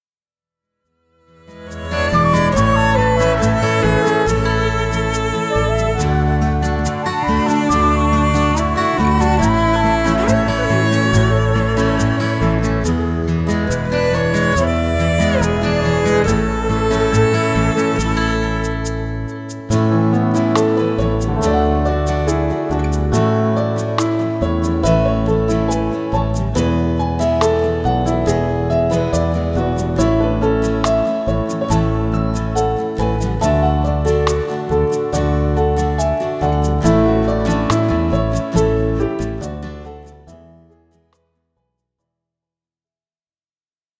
Play-Back